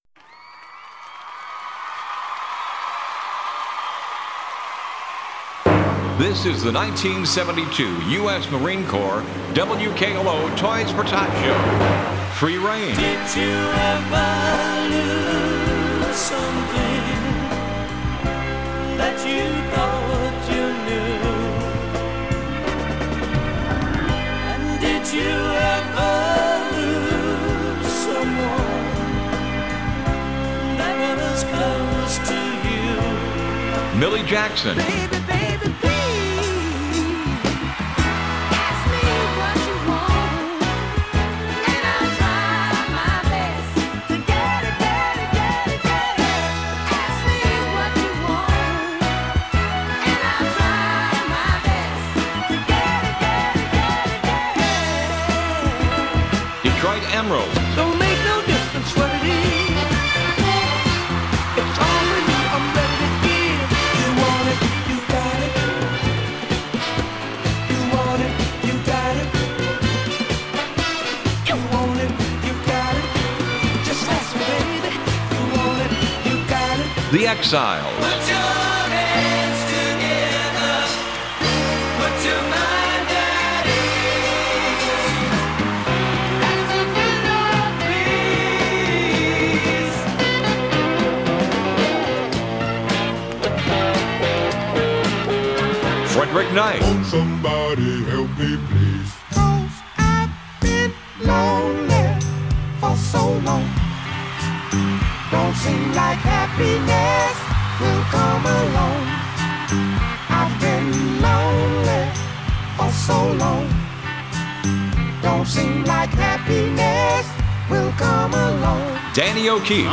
WKLO 1972 Toys for Tots Audio Collage